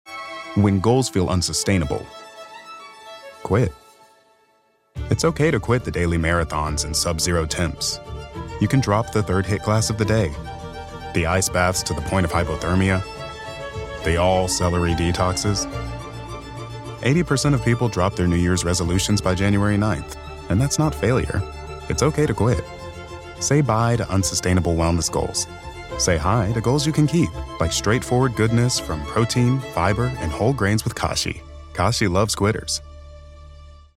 AAVE, General English(British), US Southern, Transatlantic
Kashi Spot - Kashi Hates Quitters.mp3